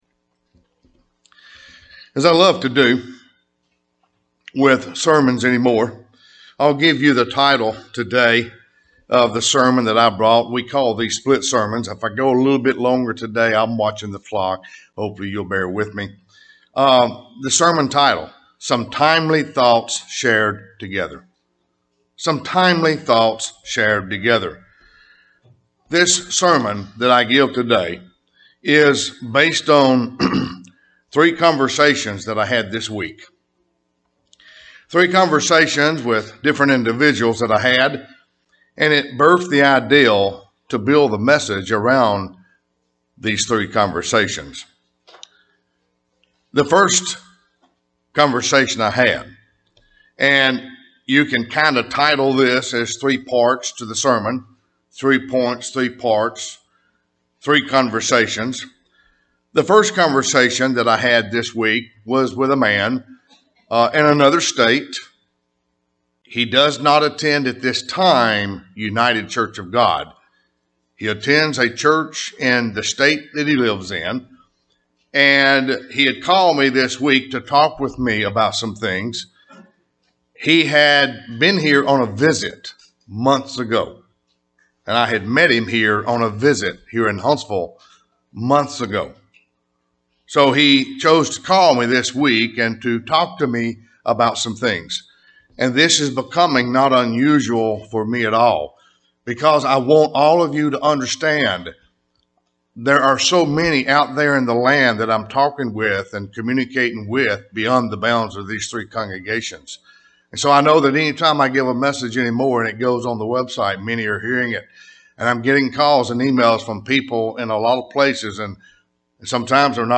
In this sermon allow me to speak of some timely thoughts shared in three separate conversations. May this message be one of encouragement and enlightenment at this time.